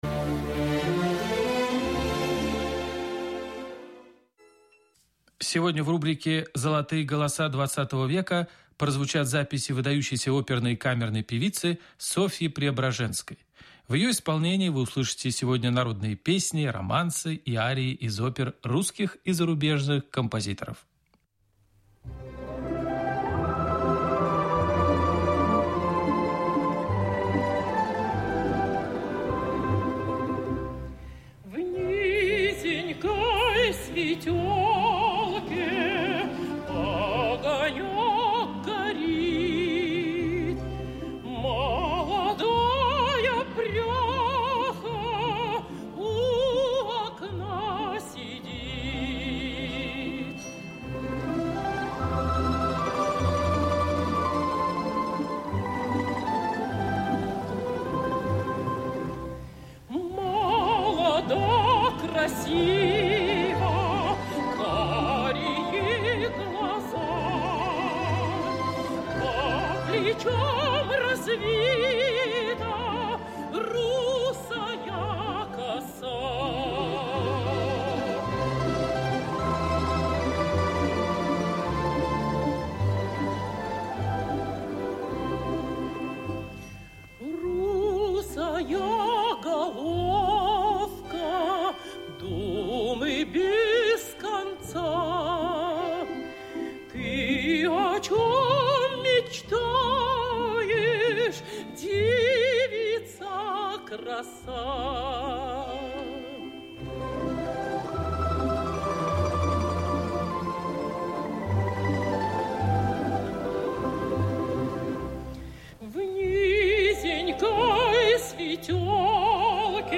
ПРЕОБРАЖЕНСКАЯ Софья Петровна [14 (27) IX 1904, Петербург - 21 VII 1966, Ленинград] - сов. певица (меццо-сопрано).
Жанр: Vocal
П. обладала голосом большого диапазона, замечательным по силе и разнообразию красок, ровно звучавшим во всех регистрах, а также ярким сценич. дарованием.